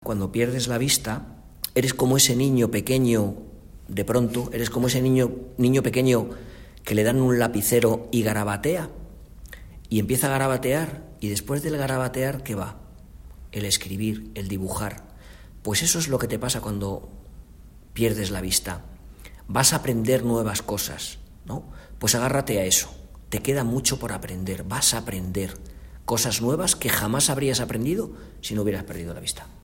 De hablar pausado y tranquilo